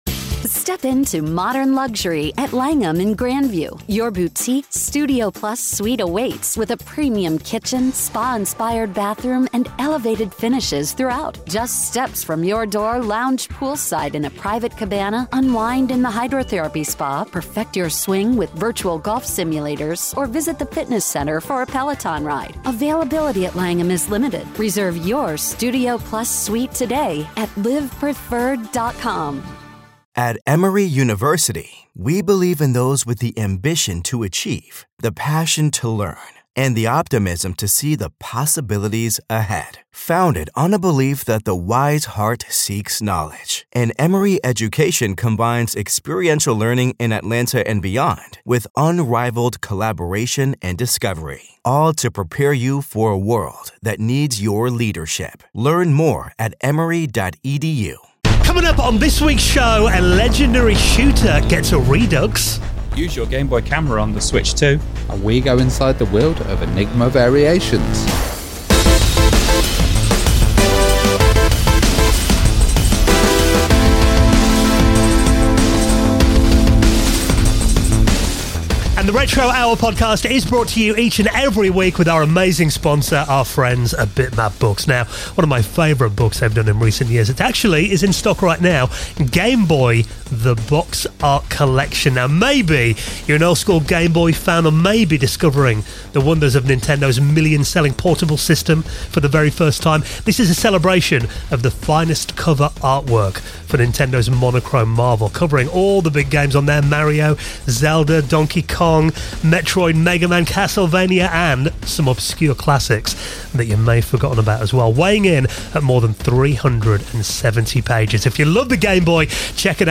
00:00 - The Week's Retro News Stories